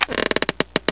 deuropen.wav